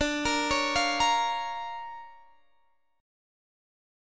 Listen to DmM7#5 strummed